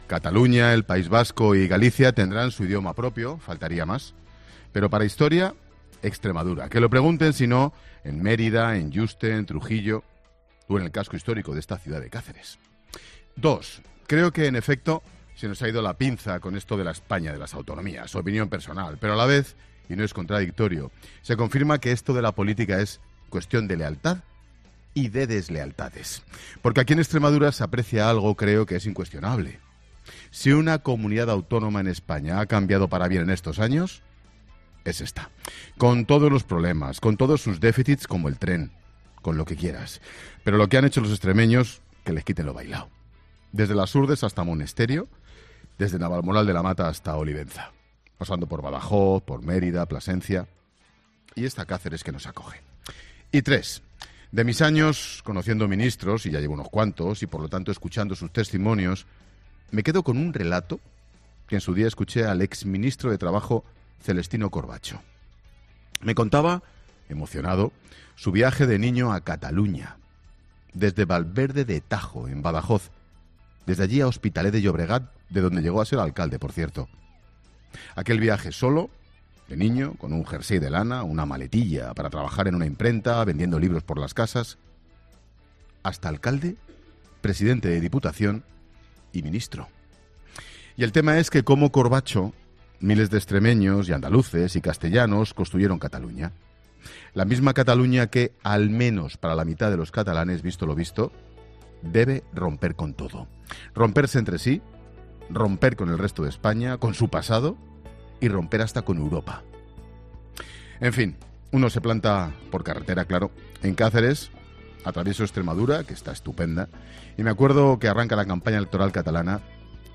AUDIO: El comentario de Ángel Expósito desde el Palacio de Congresos de Cáceres.